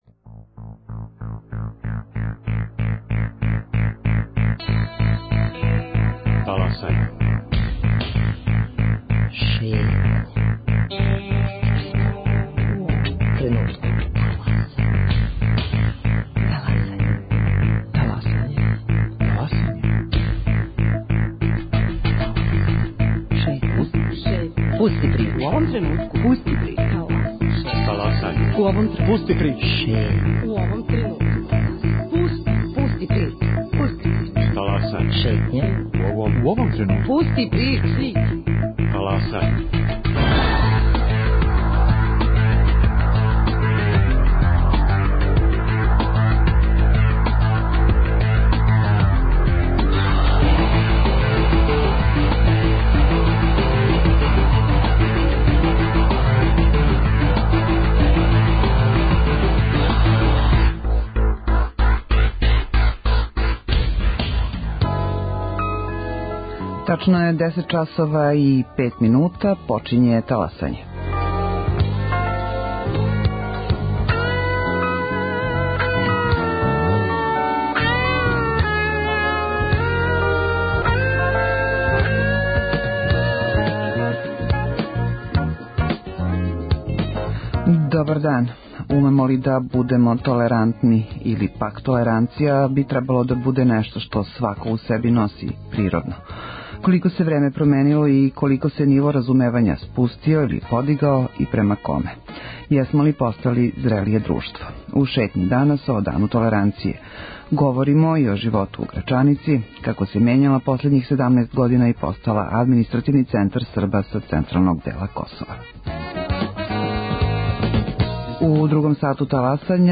У наставку емисије чућемо репортажу из Грачанице, општине у непосредној близини Приштине, која је током последњих 17 година постала административни центар за Србе из централног Косова.